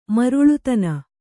♪ maruḷutana